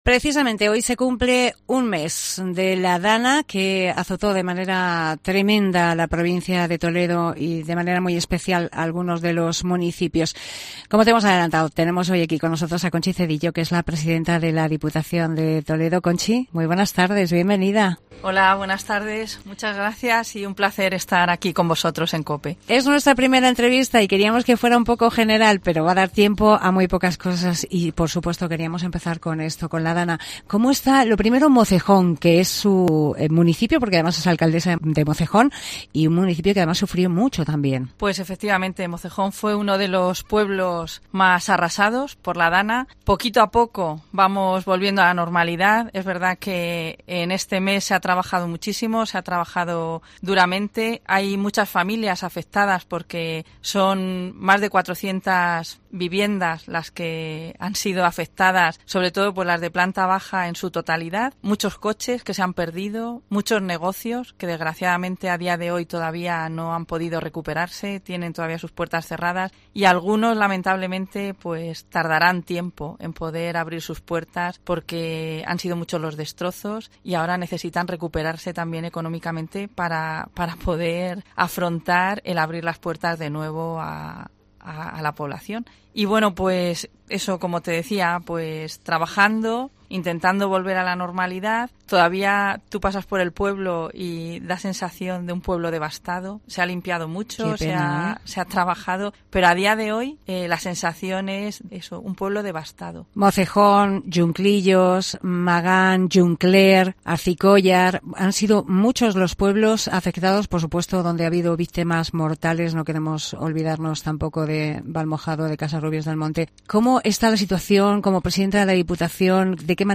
La presidenta de la Diputación de Toledo ha pasado por los micrófonos de COPE para hacer balance de estos cerca de tres meses al frente de la institución.